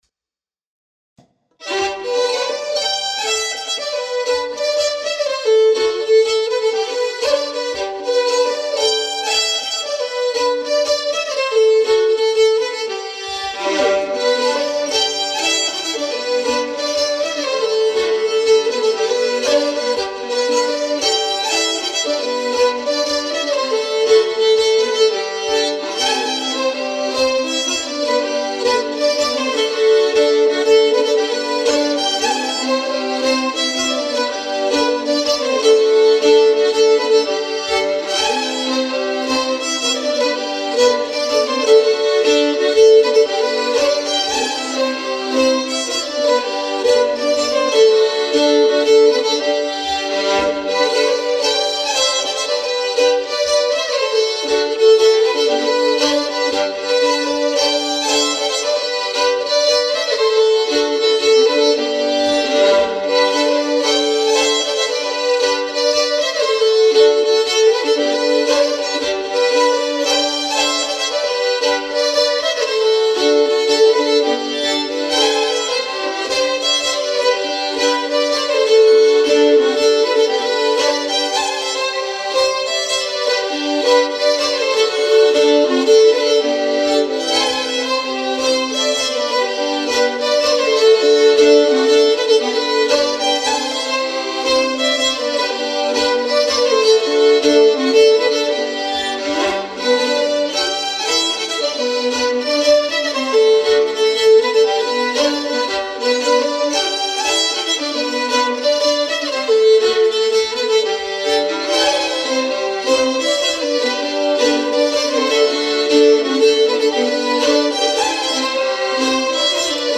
Riksspelman på Svensk Säckpipa
Polska